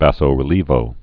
(băsō-rĭ-lēvō)